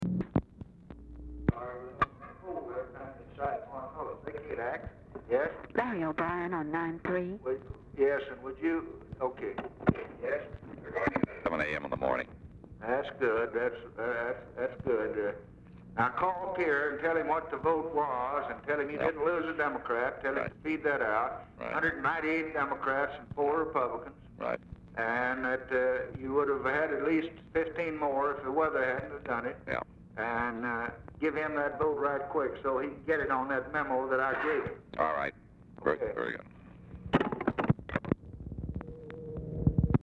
Telephone conversation # 671, sound recording, LBJ and LARRY O'BRIEN, 12/23/1963, 2:29PM | Discover LBJ
Format Dictation belt
Location Of Speaker 1 Oval Office or unknown location
Specific Item Type Telephone conversation Subject Congressional Relations Foreign Aid Legislation Press Relations